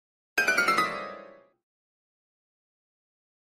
Comedy Piano Chromatic Run Up 2 - Descent